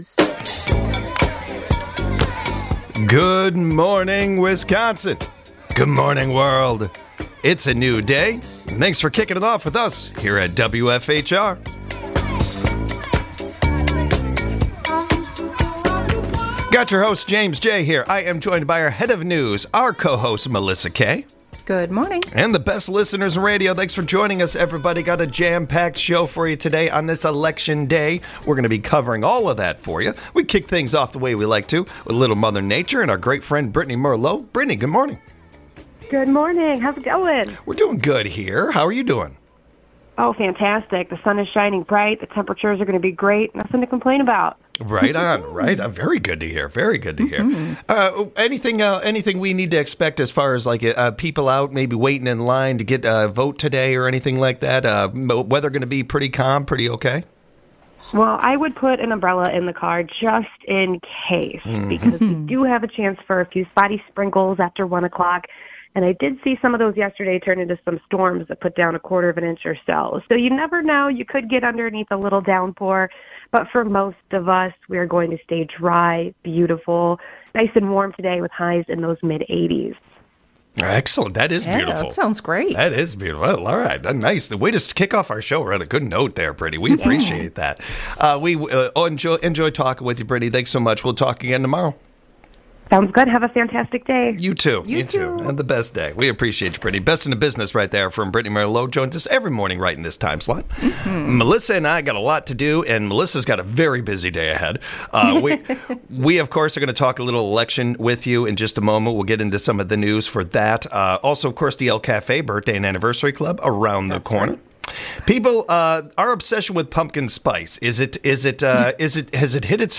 Co-hosts